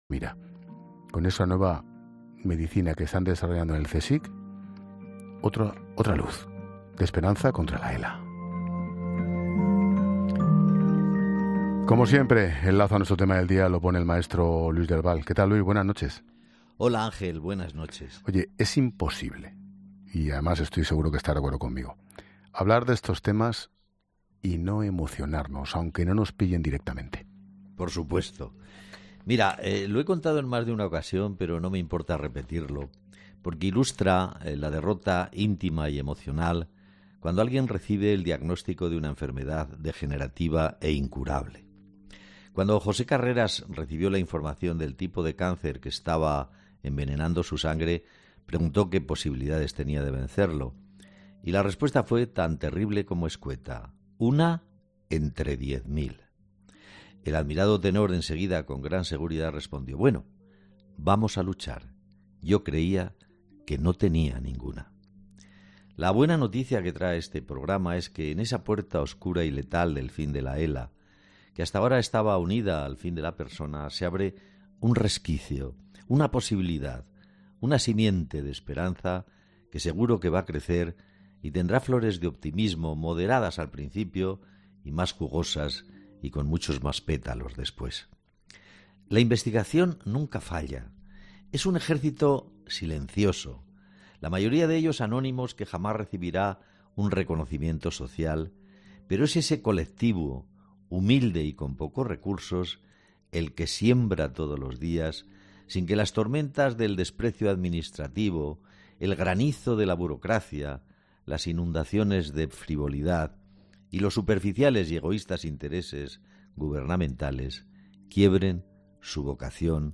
Como cada noche, el maestro Luis del Val ha puesto el lazo al tema del día en 'La Linterna' con Ángel Expósito.